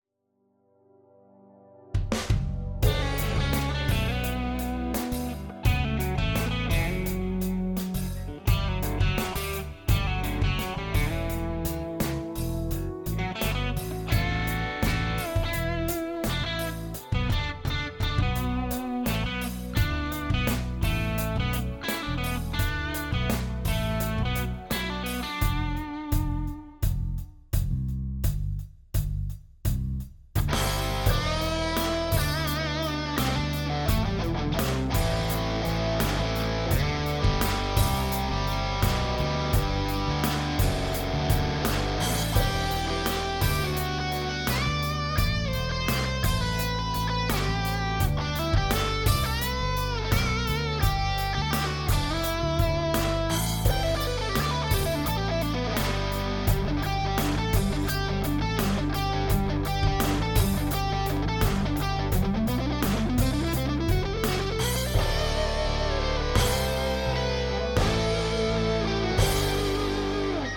Записывал в Ardour, живая только гитара - остальное прописывал плагинами. Семплов не использовал. Мастеринг и сведение отсутствуют как явление потому что я в них не умею и пока только курю пособия, но опять же, наступила какая-то тотальная нехватка времени.